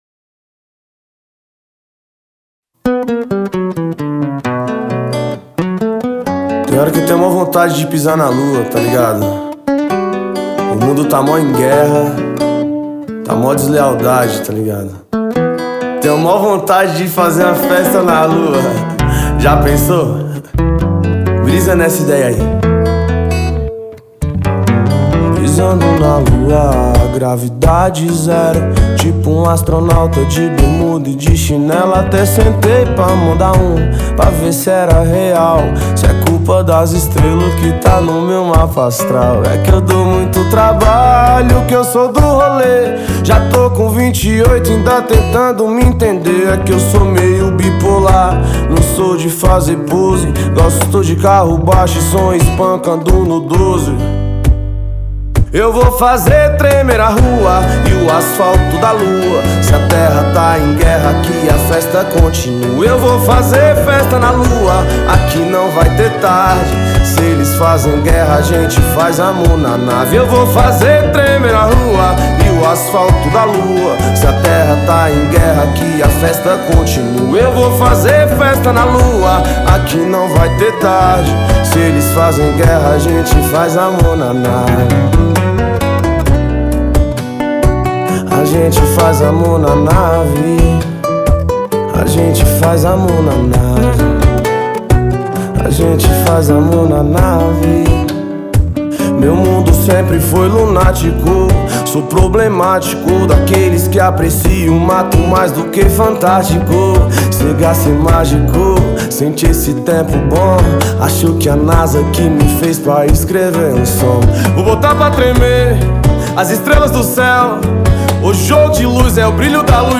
2025-03-17 19:30:35 Gênero: Rap Views